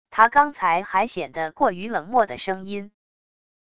他刚才还显得过于冷漠的声音wav音频生成系统WAV Audio Player